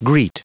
Prononciation du mot greet en anglais (fichier audio)
Prononciation du mot : greet